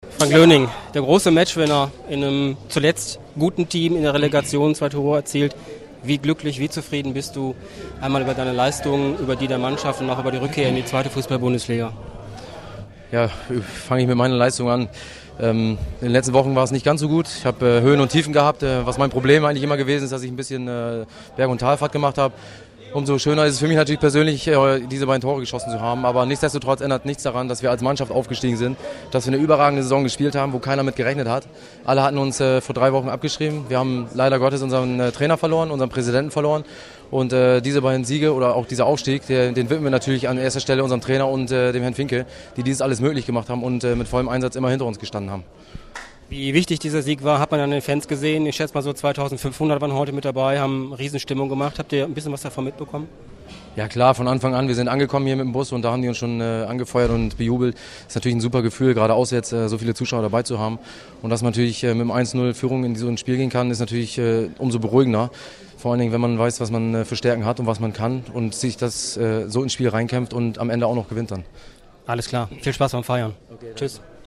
Audiokommentar